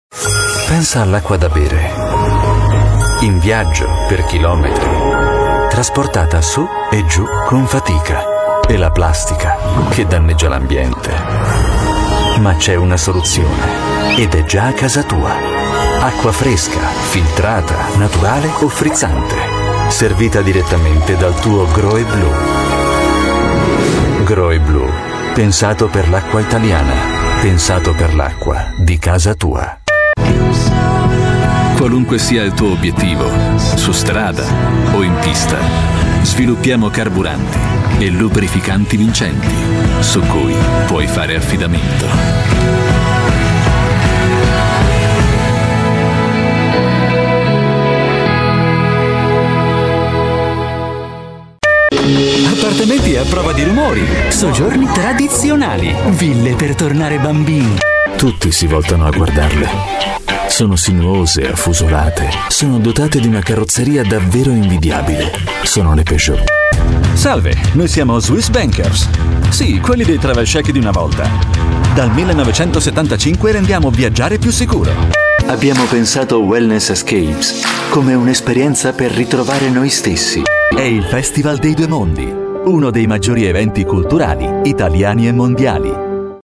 İtalyanca Seslendirme
Profesyonel İtalyanca Seslendirme ve İtalyanca Dublaj Hizmeti